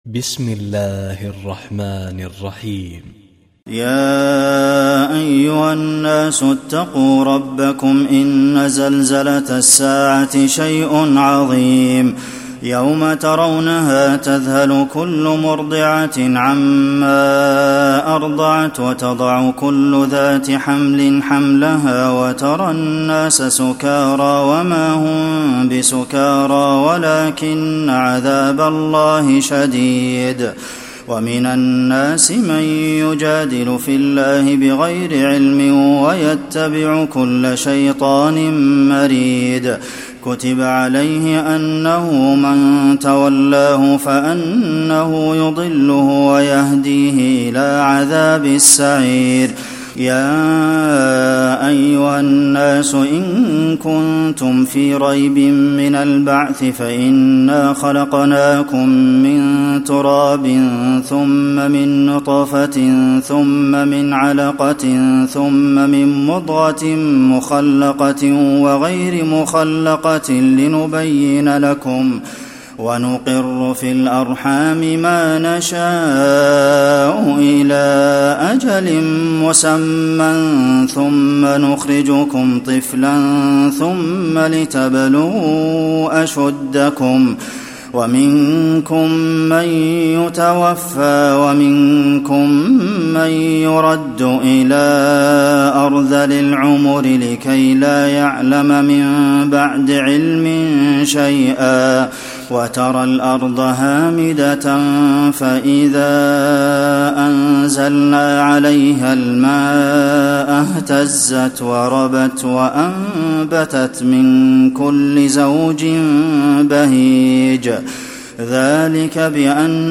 تراويح الليلة السادسة عشر رمضان 1435هـ سورة الحج كاملة Taraweeh 16 st night Ramadan 1435H from Surah Al-Hajj > تراويح الحرم النبوي عام 1435 🕌 > التراويح - تلاوات الحرمين